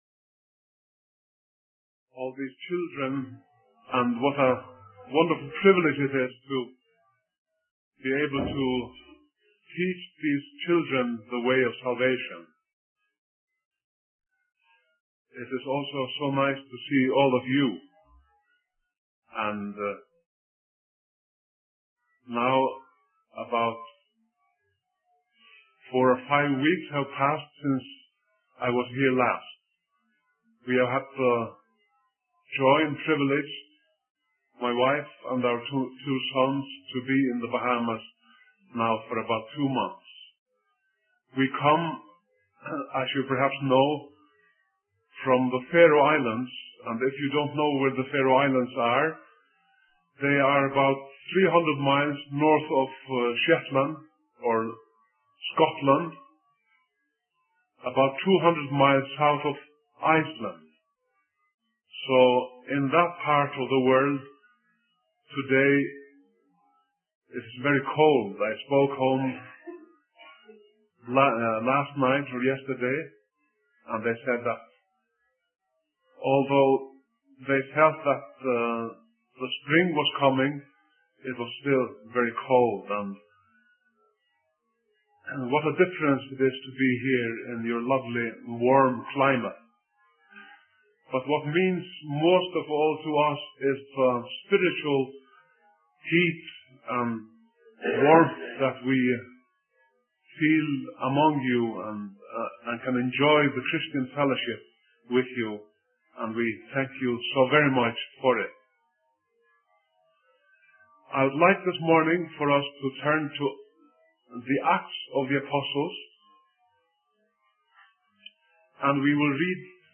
In this sermon, the preacher begins by expressing gratitude for the opportunity to teach children about salvation.